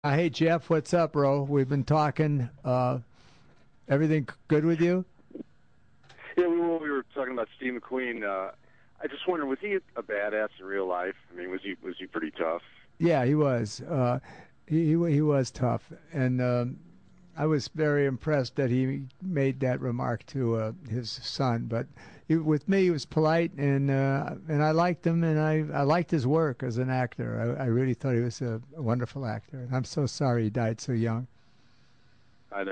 Dans son émission de radio hebdomadaire "CRN DIGITAL TALK SHOW" Robert Conrad